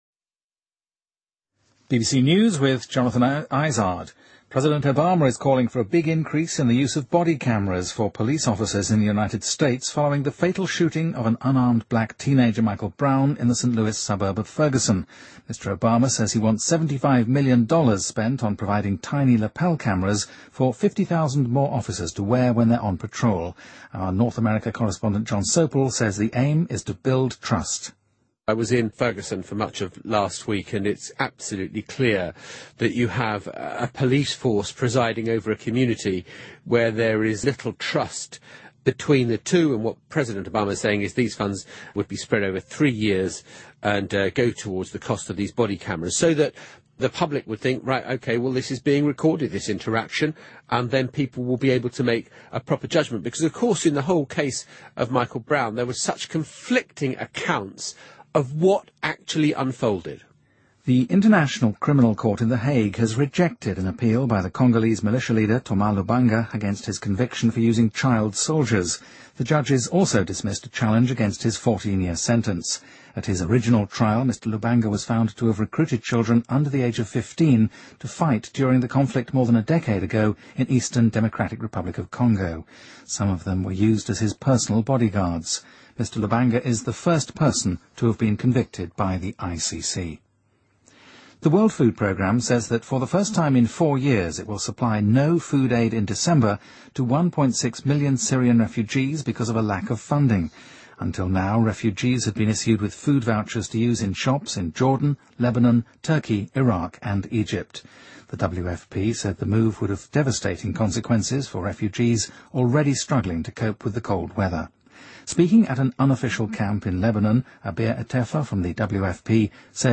BBC news,牙国际刑事法庭否决了关于刚果民兵领导人卢班加使用童子军的上诉